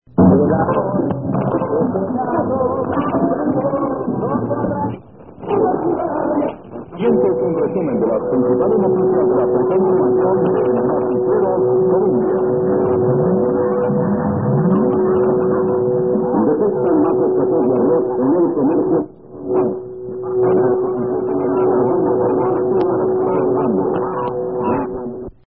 All recordings were made in Santa Barbara, Honduras (SB) using a Yaesu FRG-7 receiver.